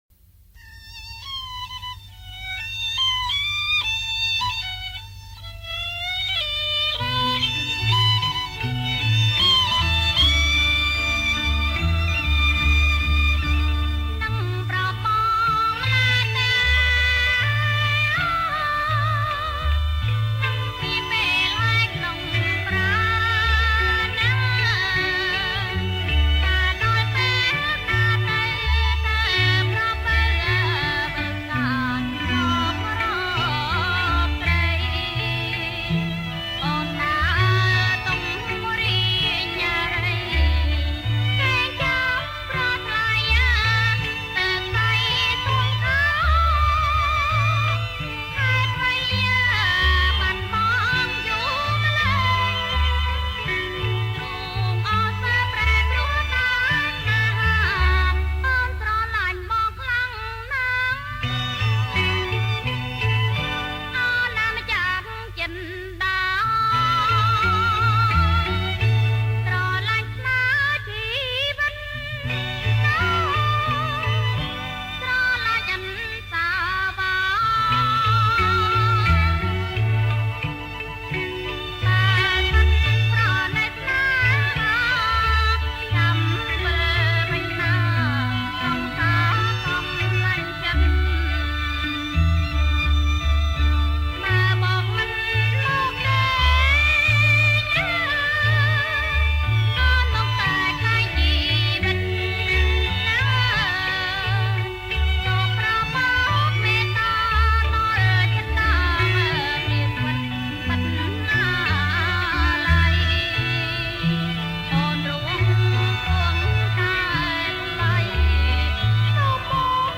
ប្រគំជាចង្វាក់ Slow Rock